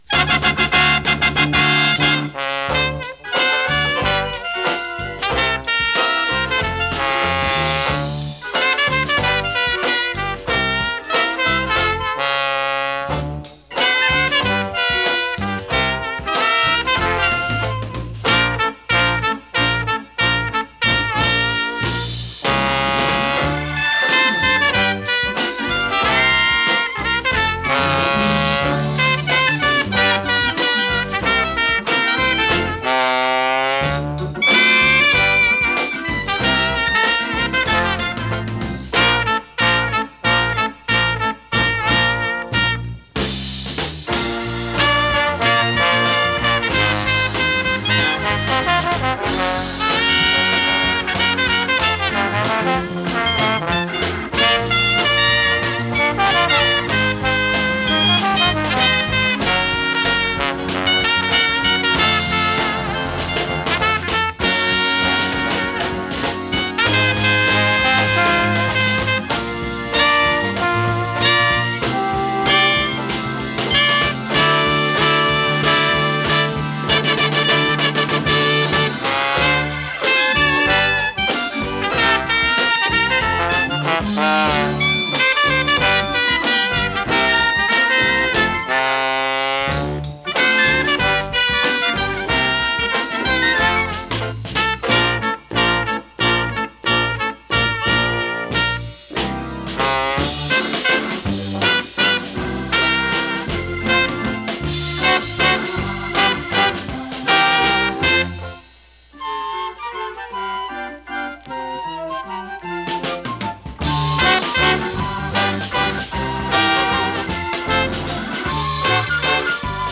60sポップスの数々をノスタルジックなスウィング・ジャズでカバー！